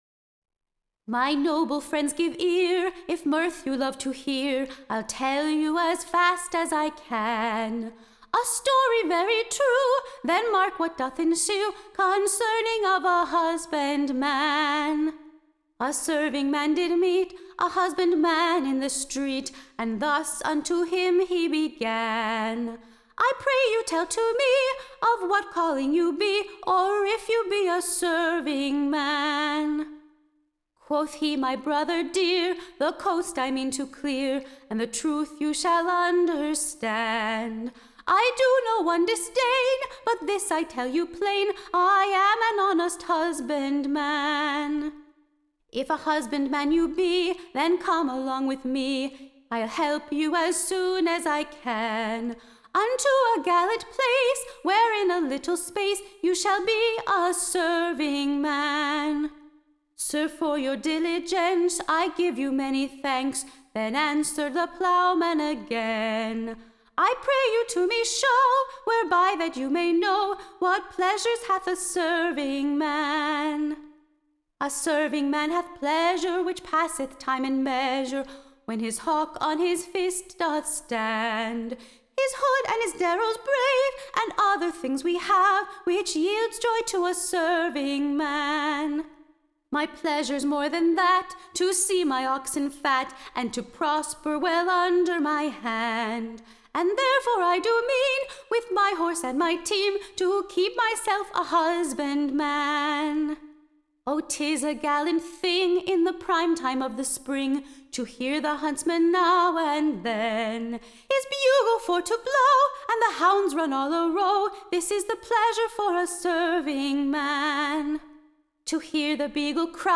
Recording Information Ballad Title God speed the Plow, and bless the Corn-mow. / OR, / A new merry Dialogue between a Plowman and a Servingman.